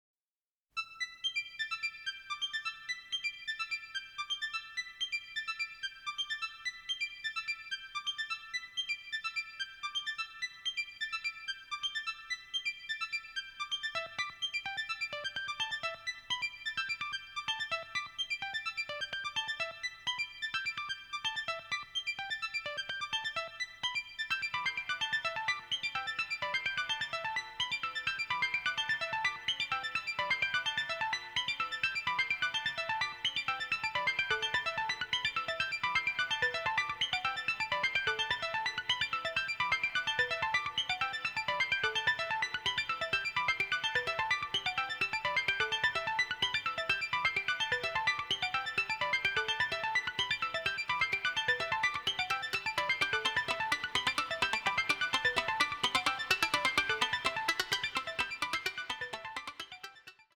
bizarre sci-fi score
electronic music